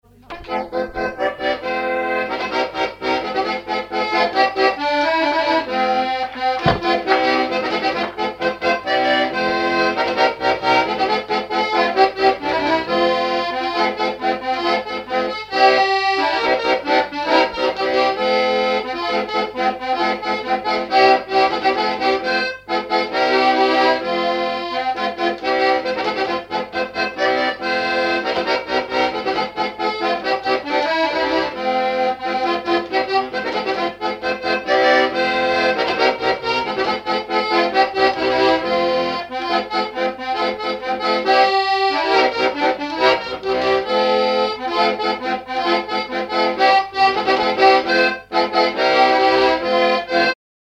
Localisation Noirmoutier-en-l'Île (Plus d'informations sur Wikipedia)
Fonction d'après l'analyste danse : polka ;
Usage d'après l'analyste gestuel : danse ;
Catégorie Pièce musicale inédite